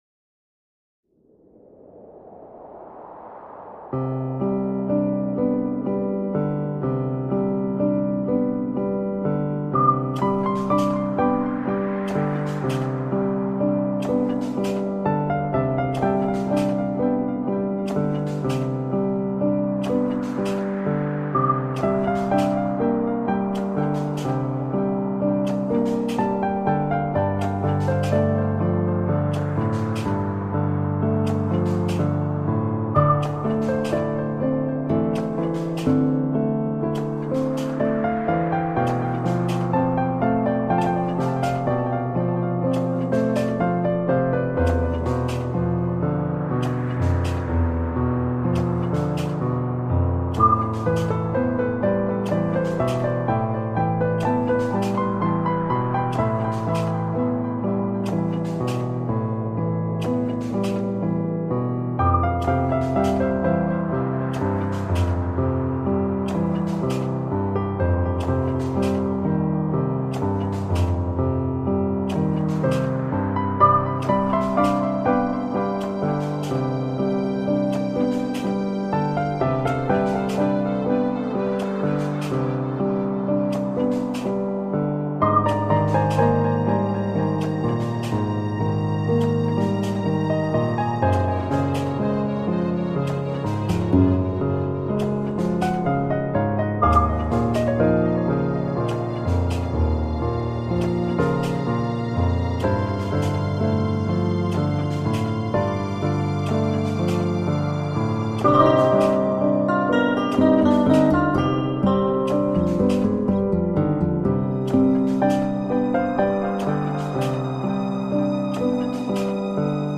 3. ６８拍子「某座湖面上巨人都市之幻影中」